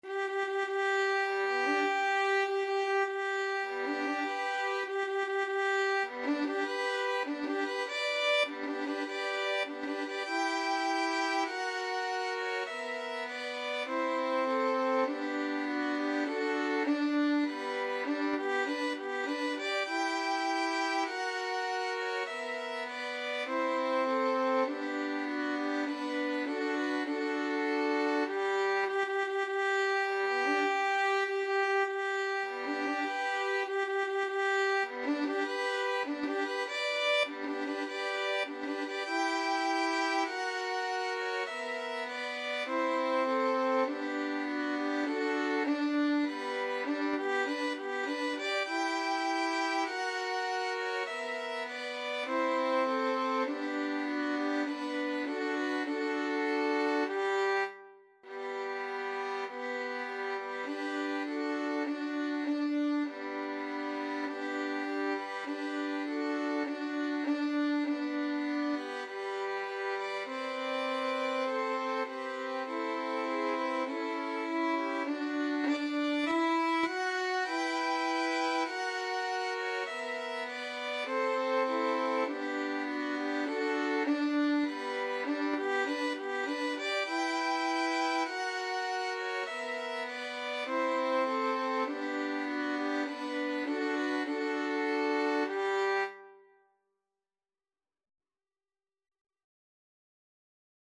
Andante maestoso =100
4/4 (View more 4/4 Music)
Violin Trio  (View more Easy Violin Trio Music)
Classical (View more Classical Violin Trio Music)